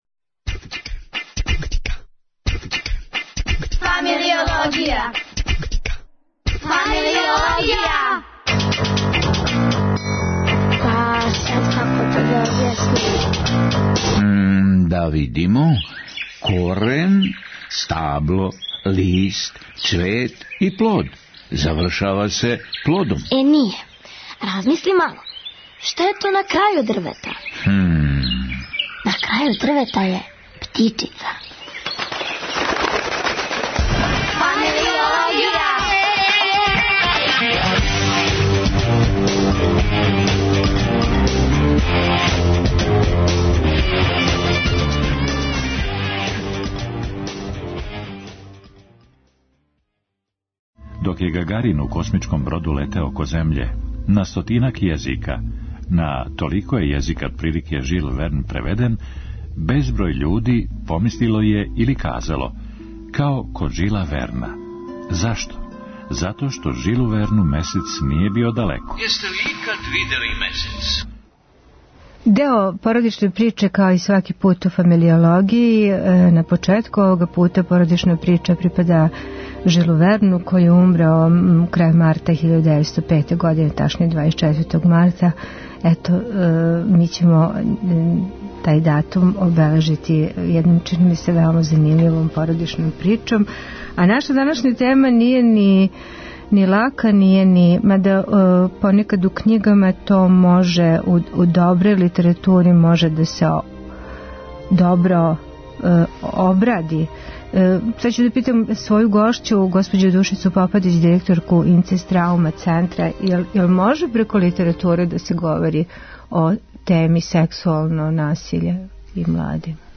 Предлог за овај разговор послала нам је мајка чија деца иду у основну школу.